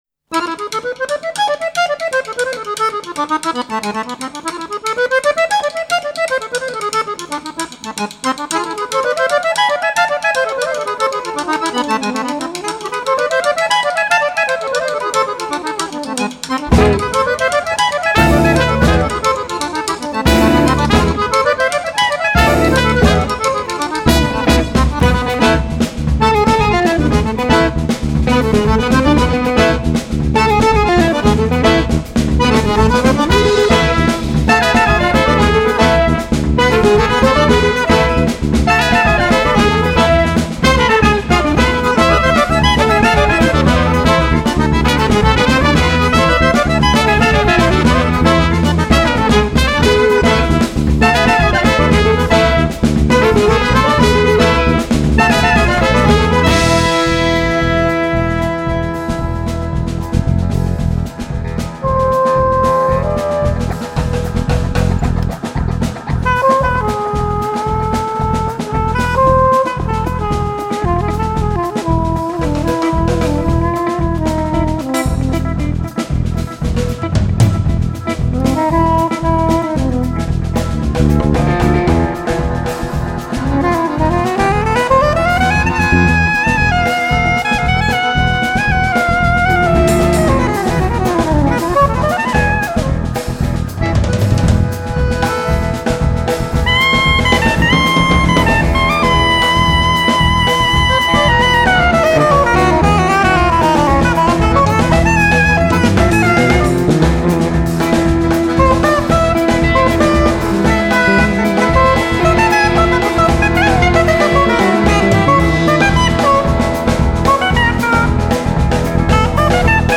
trompette, bugle
saxophone
accordeons
bass
drums
guitars